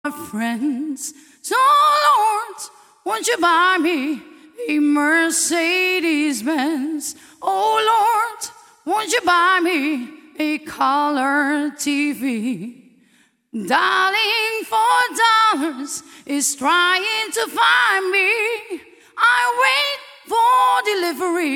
modernized version